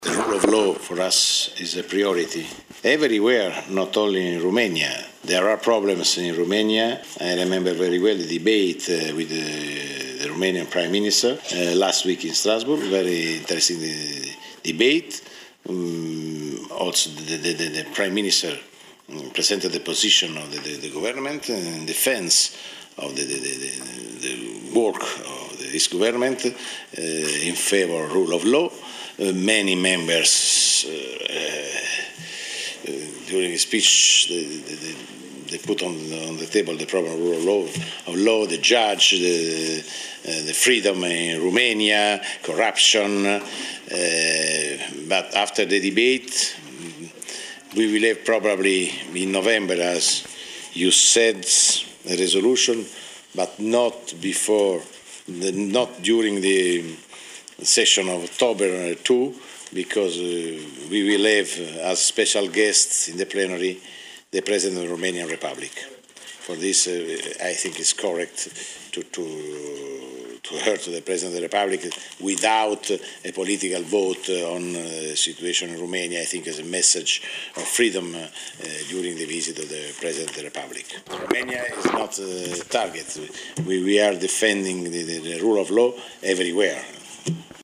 Afirmația a fost făcută de președintele Parlamentului European, Antonio Tajani, în cadrul unei conferințe despre regiuni și orașe în cadrul comunității europene, cu prilejul Săptămânii Europene a regiunilor.